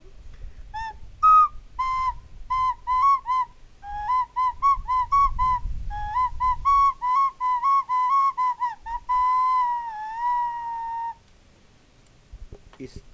Ocarina 1 GUANACASTE